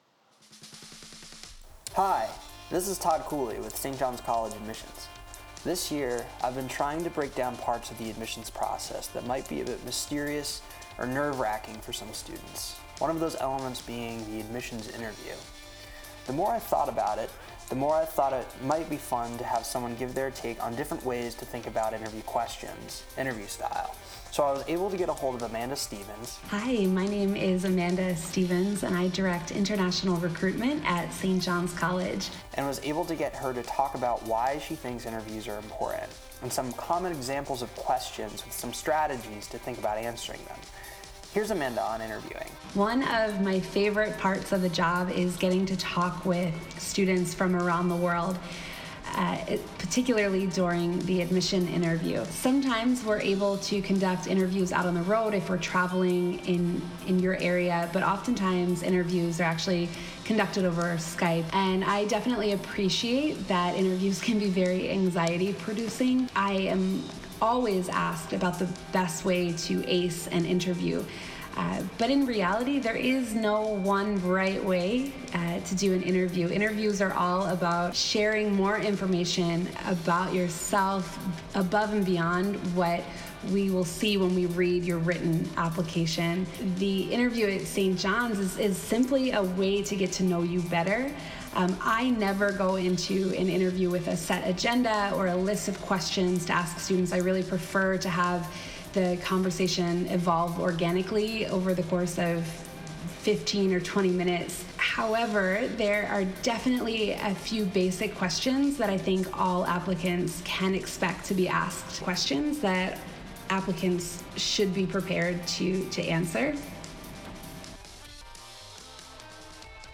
An Interview on Interviews